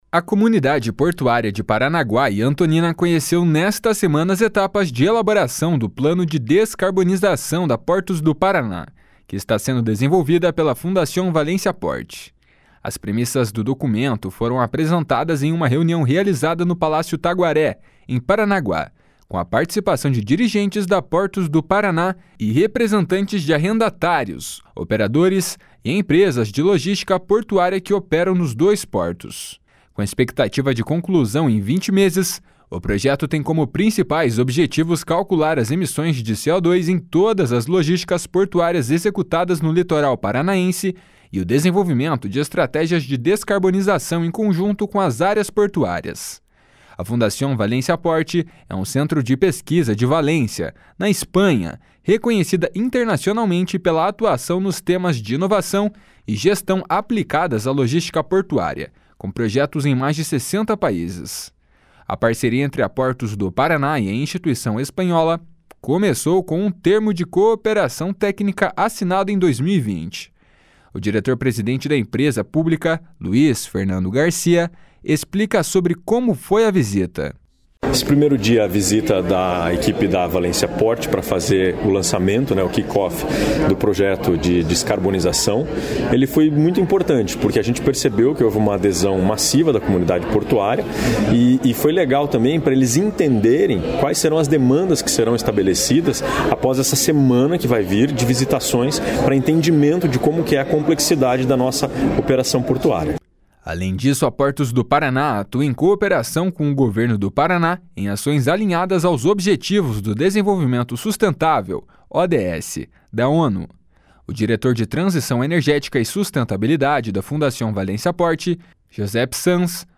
O diretor-presidente da empresa pública, Luiz Fernando Garcia, explica sobre como foi a visita.